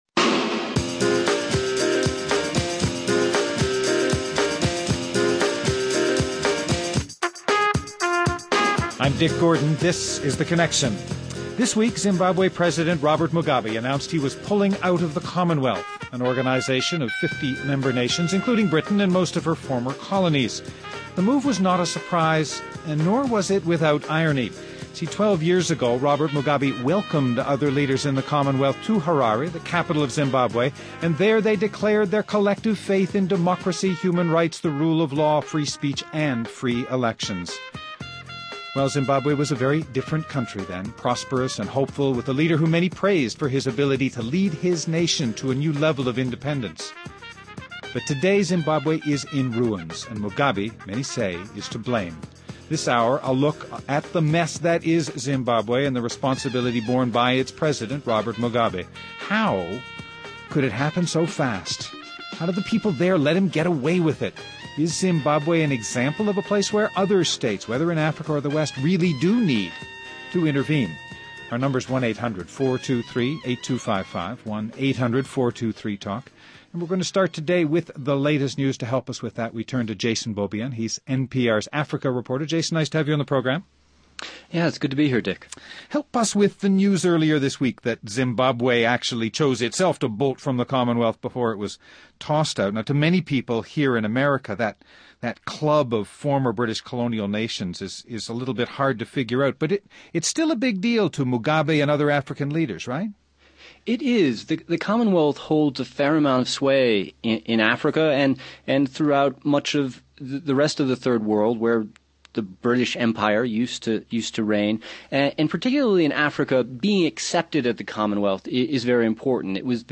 Bradley Campbell commissioner of the New Jersey Department of Environmental Protection